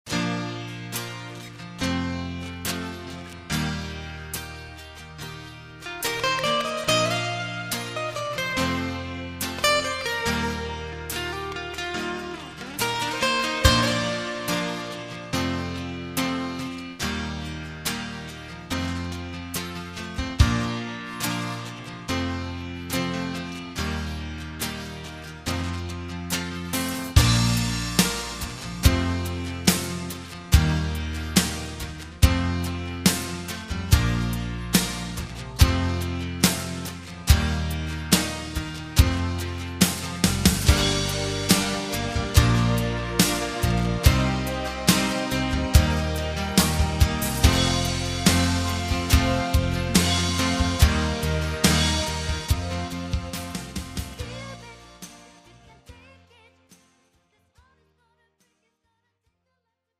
장르 pop 구분